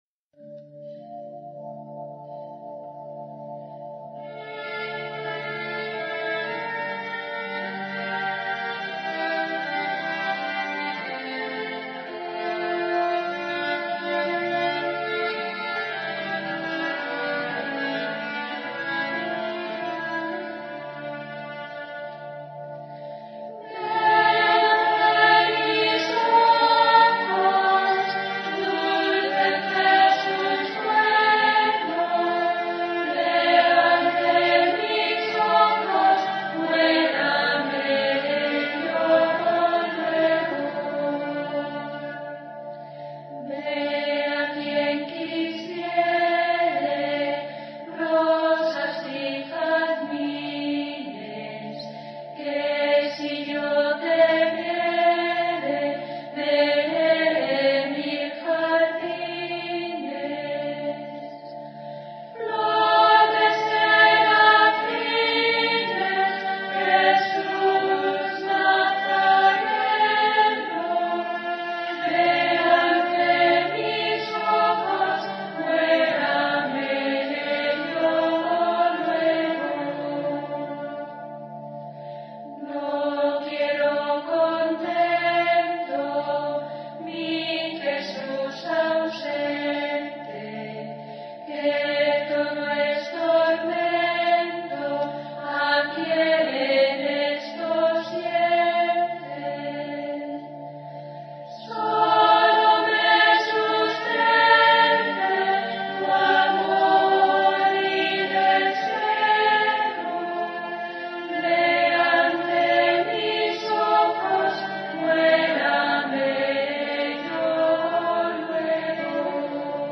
(Esta canción religiosa fue extraída de un poema muy conocido del s. XVI, de posible autor anónimo, y aunque se le ha atribuido a Santa Teresa de Jesús, al parecer pudo ser una hermana novicia quién la cantó en presencia de la santa, durante la Pascua de Resurrección de 1571.